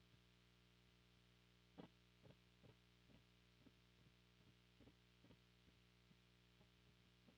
Guitar_012.wav